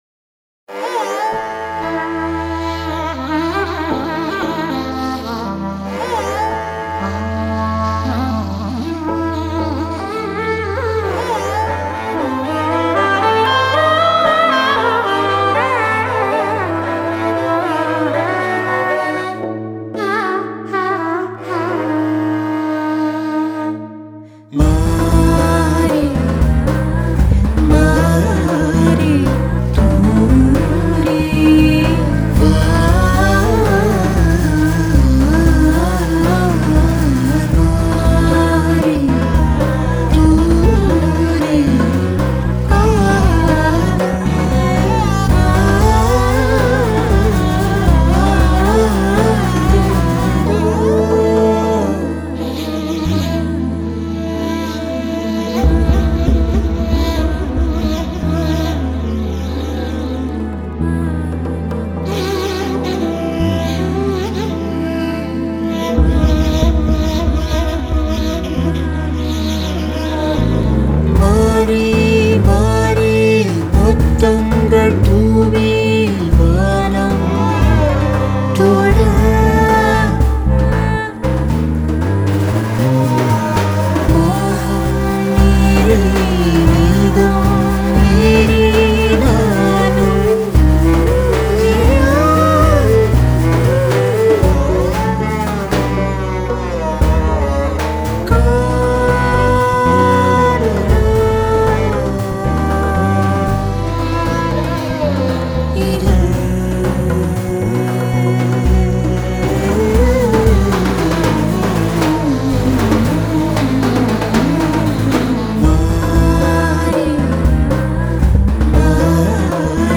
صدا‌های دل‌نشین
ژانر‌های راک، فیوژن و الکترونیک
او در تنظیم این قطعه، فضایی عمیق و تأثیرگذار ایجاد کرده است.
میزبان ضبط این قطعه در هند بوده